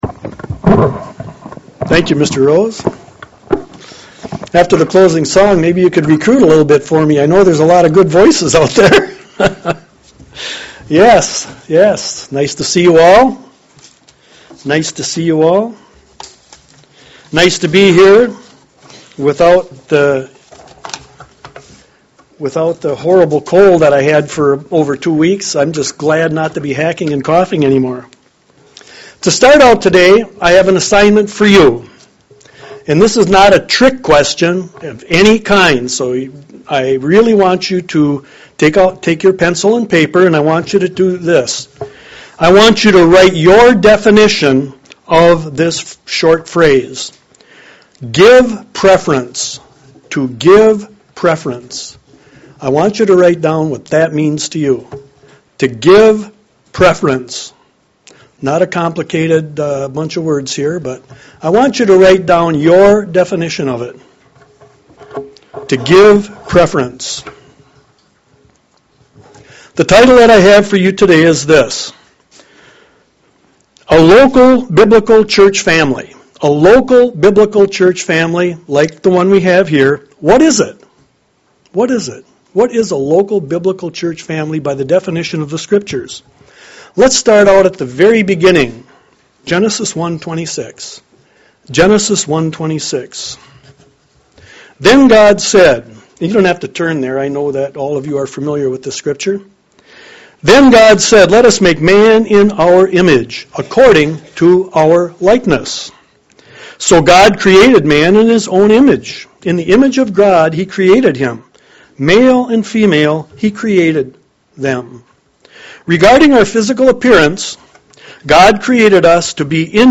UCG Sermon Studying the bible?
Given in Lansing, MI